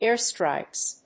/ˈɛˌrstraɪks(米国英語), ˈeˌrstraɪks(英国英語)/